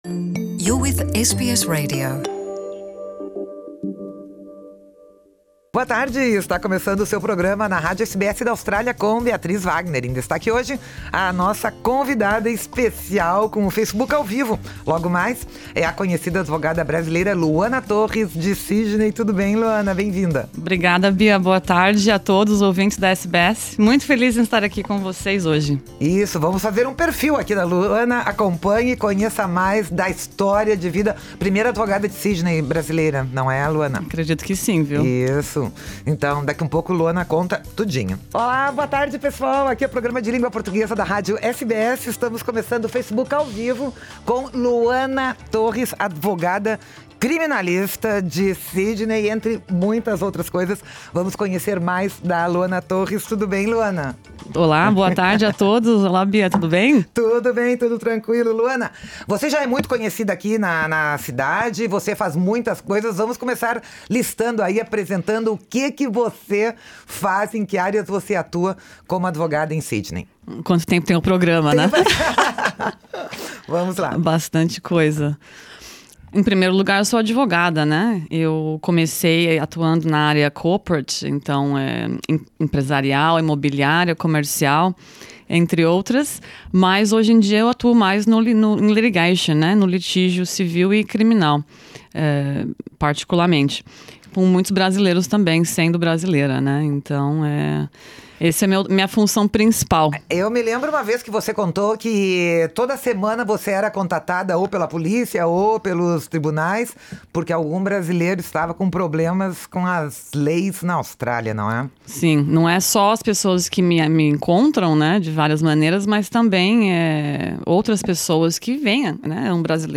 Confira o bate-papo completo no estúdio